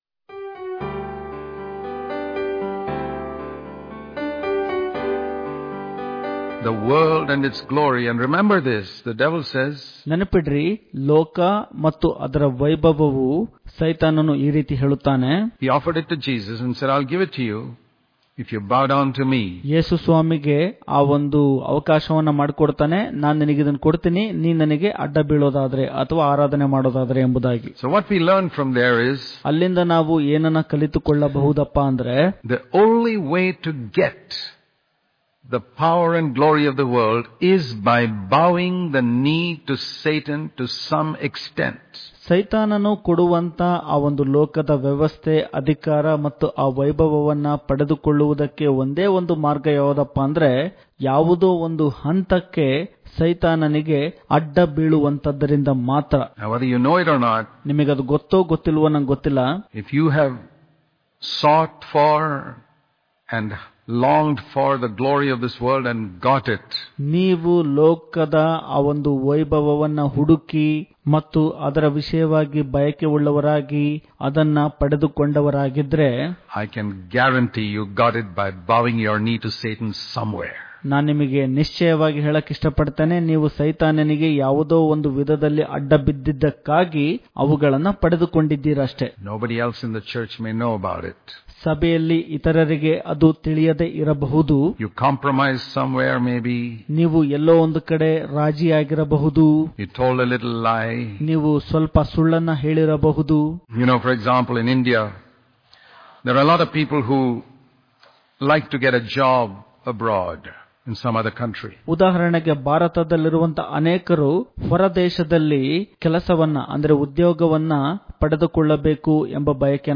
September 10 | Kannada Daily Devotion | Do Not Seek The Glory Of This World - Part 2 Daily Devotions